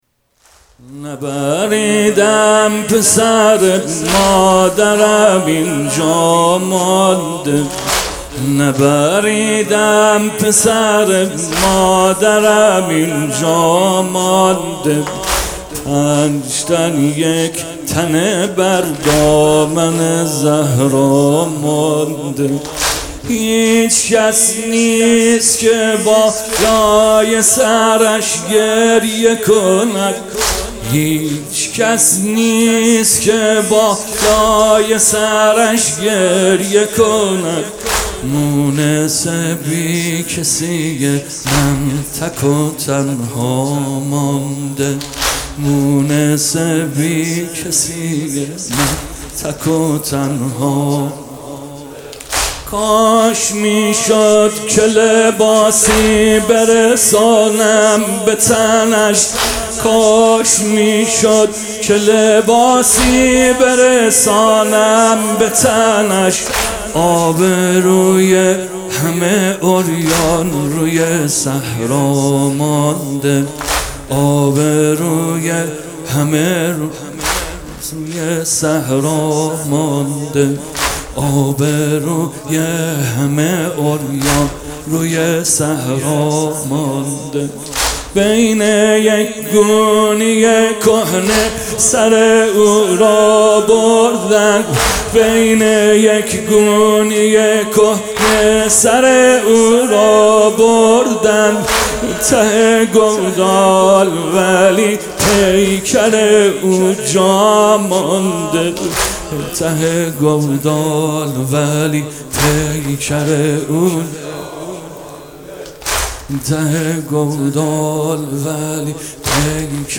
شام غریبان حسینی
هیئت فدائیان حسین (ع) اصفهان